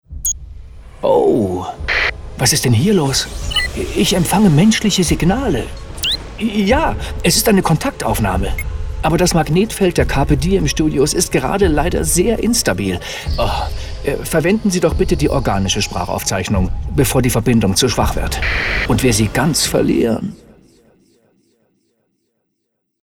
Kreative Telefonansage
Telefonansagen mit echten Stimmen – keine KI !!!